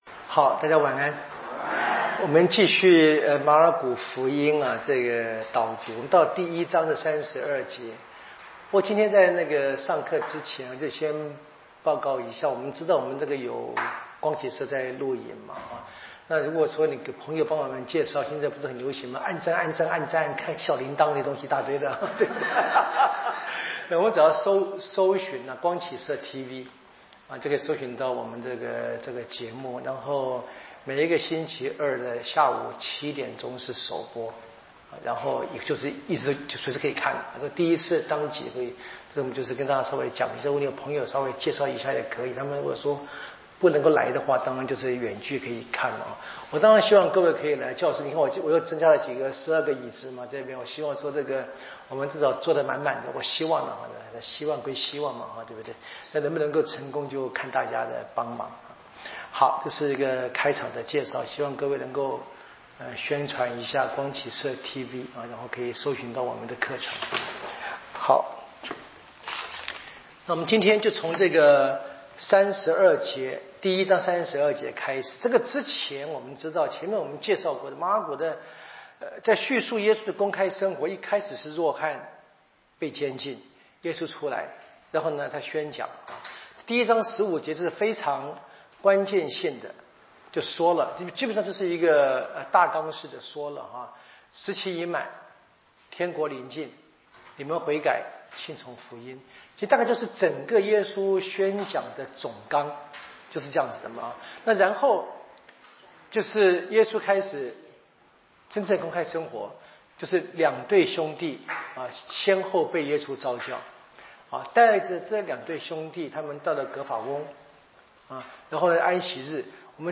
圣经讲座】《马尔谷福音》